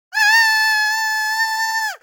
Hog Rider Scream Sound Effect Download: Instant Soundboard Button